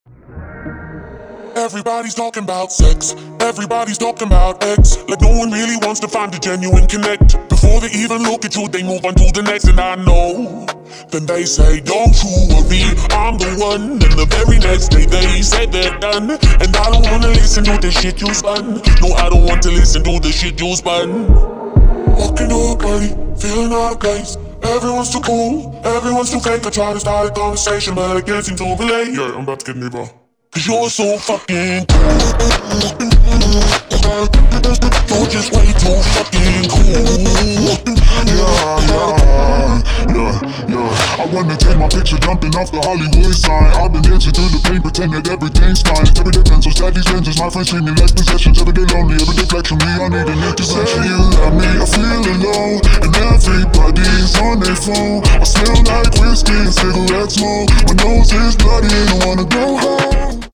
• Качество: 320, Stereo
мужской голос
чувственные
качающие
цикличные
Чувственный рингтон